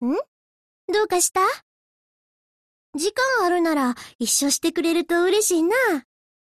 文件 50 KB =={{int:filedesc}}== 游戏语音 =={{int:license-header}}== {{fairuse}} 1